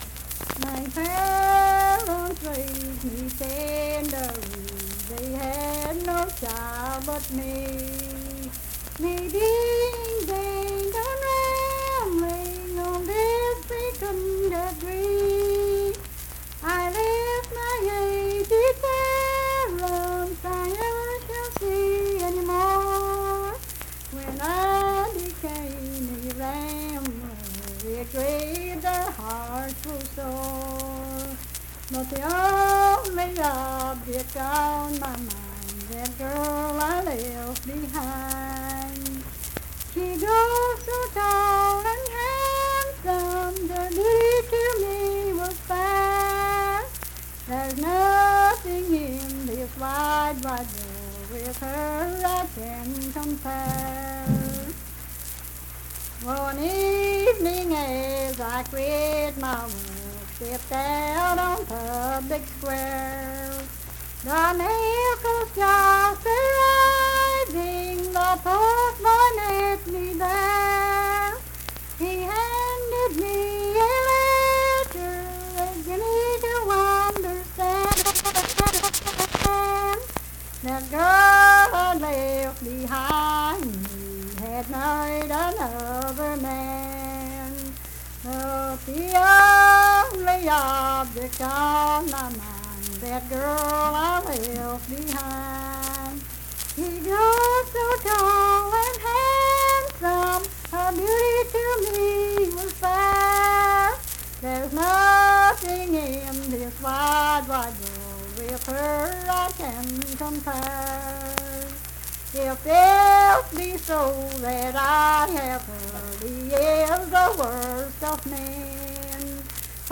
Unaccompanied vocal music performance
Verse-refrain 3d(4) &Rd(3).
Voice (sung)
Kirk (W. Va.), Mingo County (W. Va.)